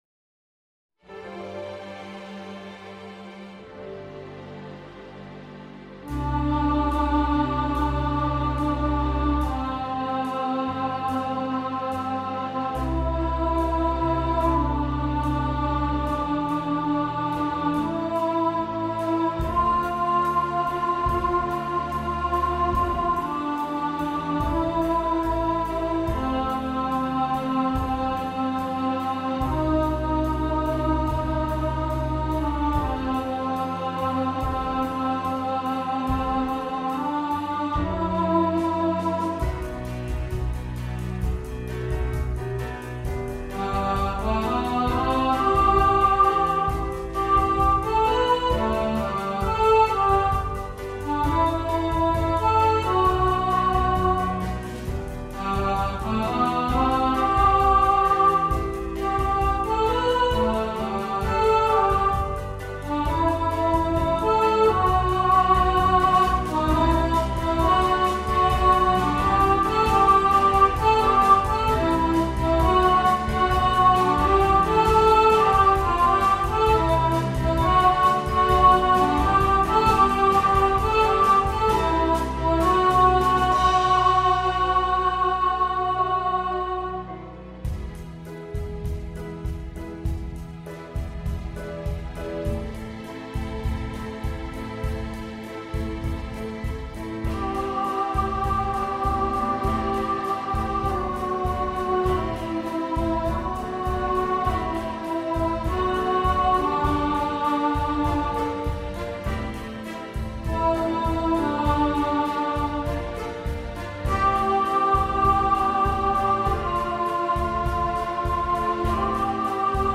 Bacharach Medley – Alto | Ipswich Hospital Community Choir